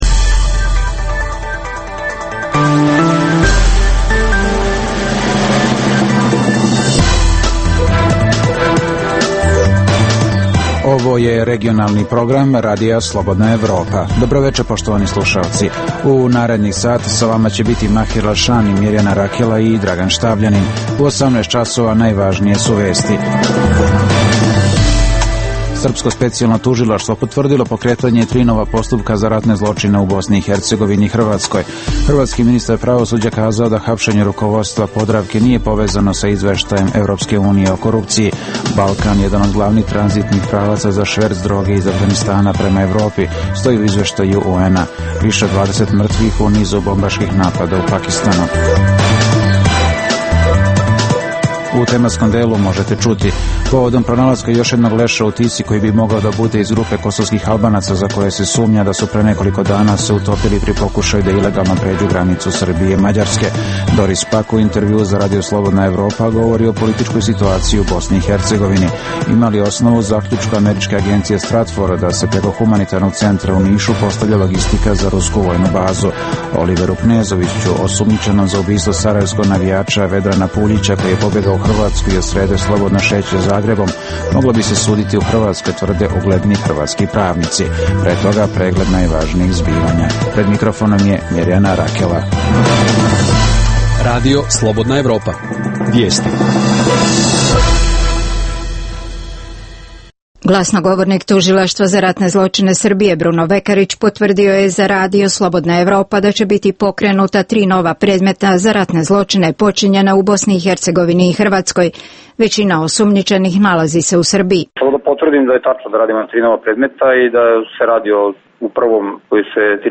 Doris Pak u intervjuu za Radio Slobodna Evropa o političkoj situaciji u BiH. - Ima li osnova u zaključku američke agencije STRATFOR da se preko humanitarnog centra u Nišu postavlja logistika za rusku vojnu bazu